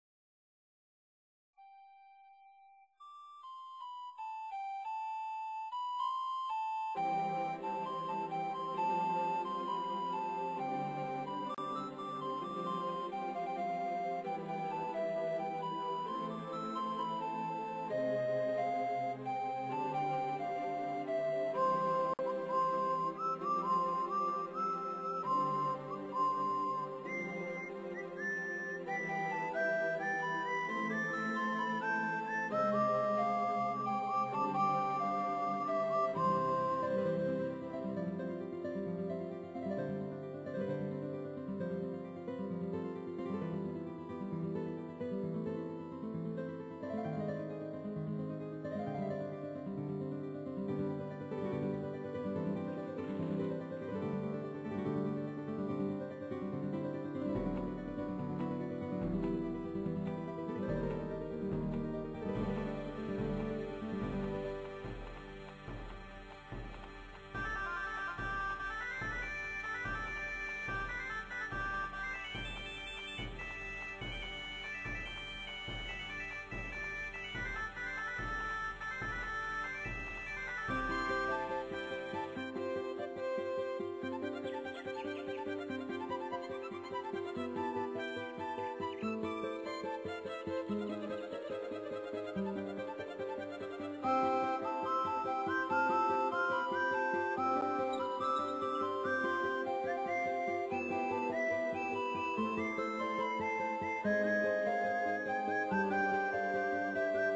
tema de fundo